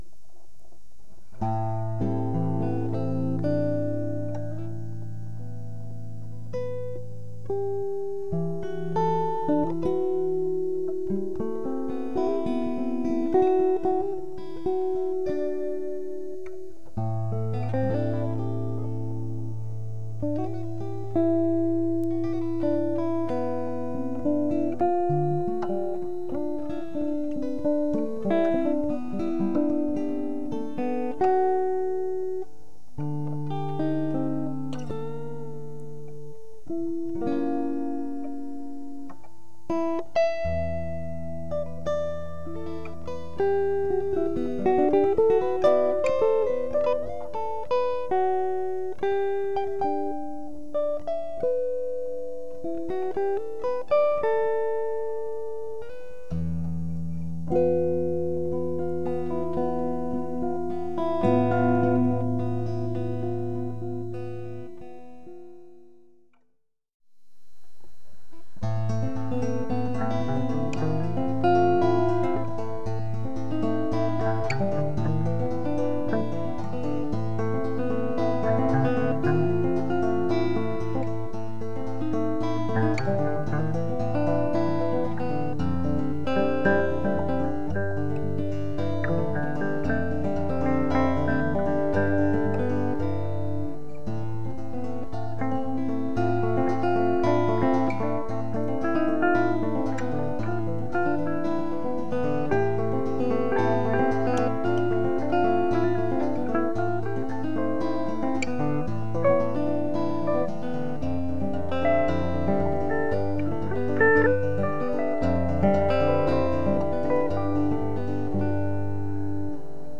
Gitarren